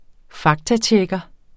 Udtale [ -ˌtjεgʌ ]